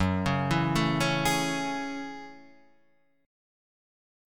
F#m9 chord